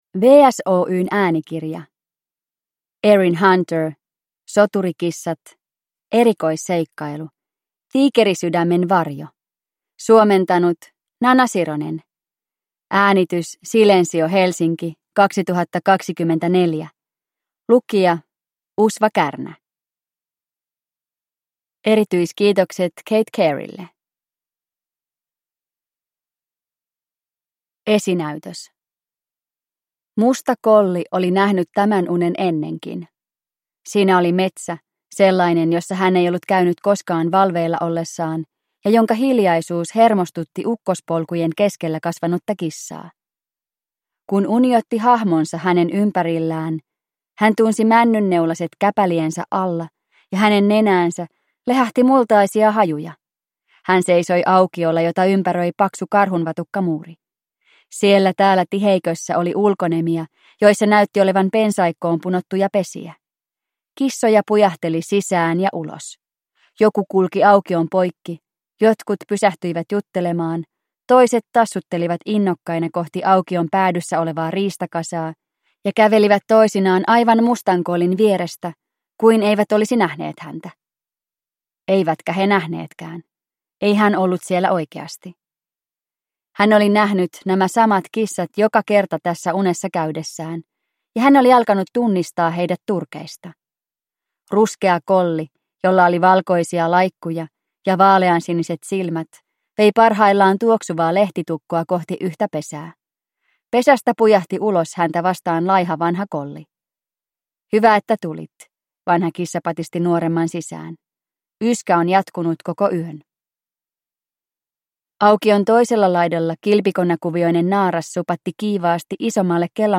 Soturikissat: Erikoisseikkailu: Tiikerisydämen varjo – Ljudbok